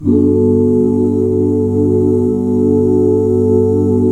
GMAJ7 OOO -L.wav